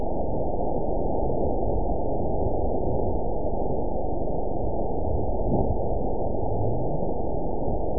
event 912529 date 03/28/22 time 20:29:32 GMT (3 years, 1 month ago) score 9.34 location TSS-AB05 detected by nrw target species NRW annotations +NRW Spectrogram: Frequency (kHz) vs. Time (s) audio not available .wav